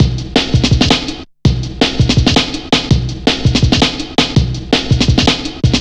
Index of /90_sSampleCDs/Zero-G - Total Drum Bass/Drumloops - 3/track 45 (165bpm)
double garage 1.wav